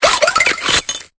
Cri de Sorbouboul dans Pokémon Épée et Bouclier.